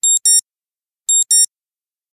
Index of /phonetones/unzipped/Pantech/Flex-P8010/alarms
PiezoAlarm.ogg